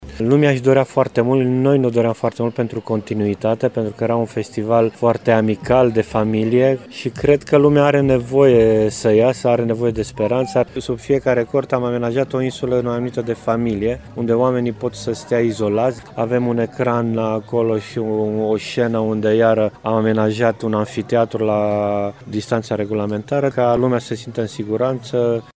Producătorul și actorul